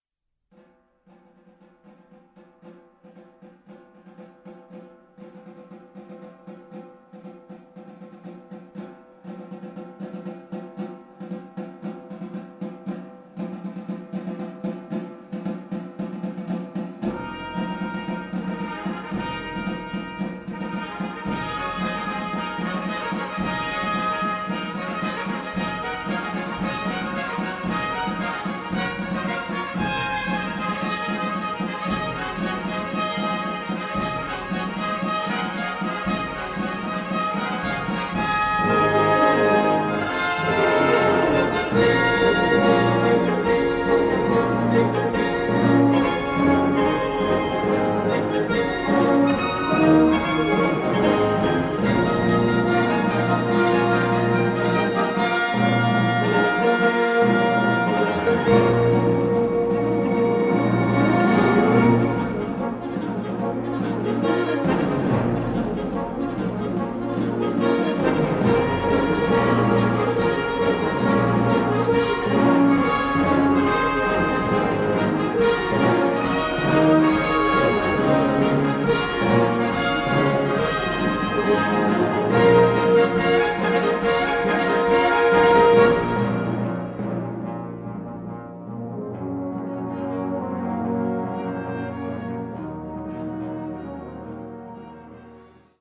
orchestra di strumenti a faito